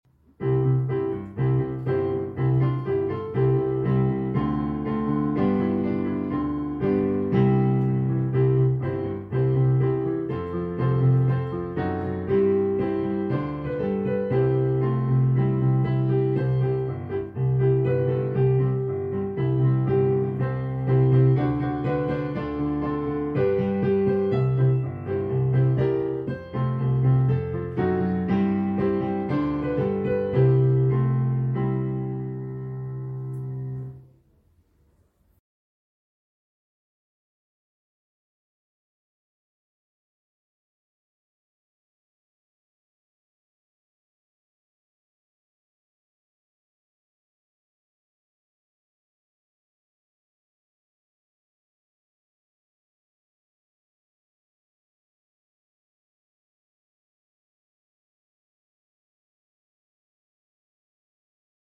When We All Get to Heaven - piano hymn